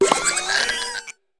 farigiraf_ambient.ogg